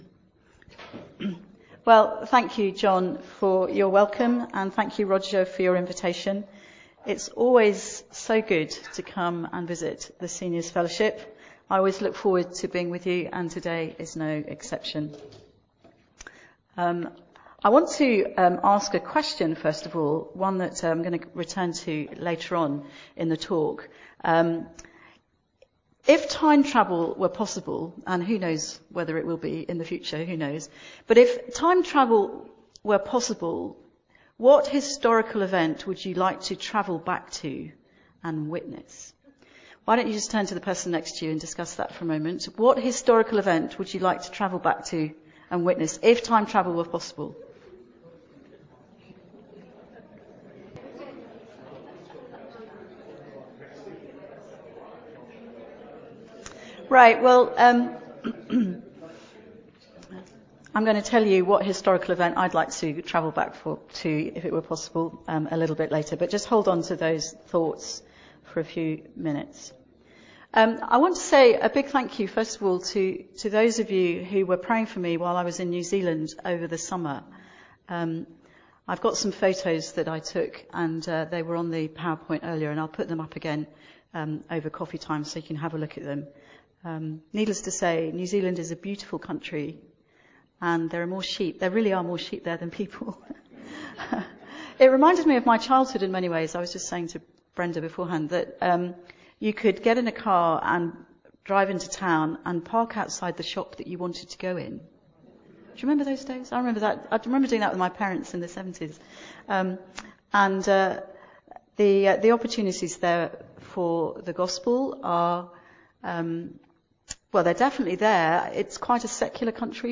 Theme: Hope for the despairing Sermon - Audio Only Search media library...